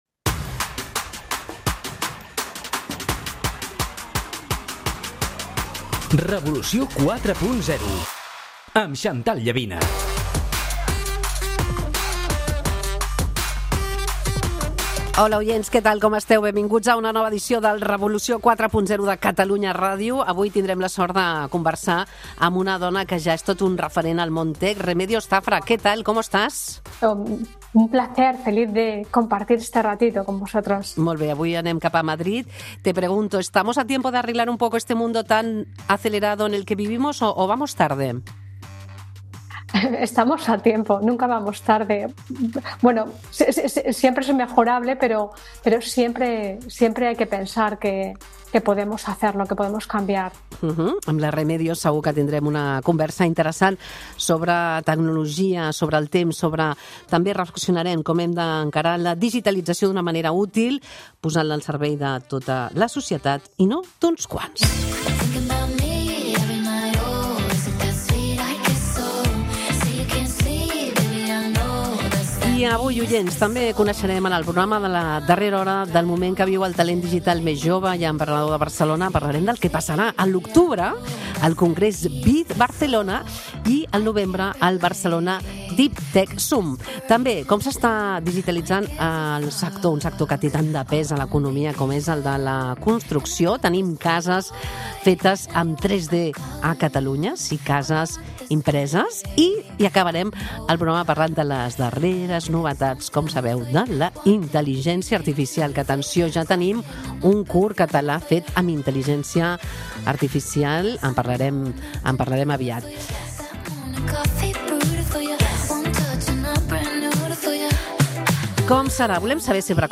Catalunya Rdio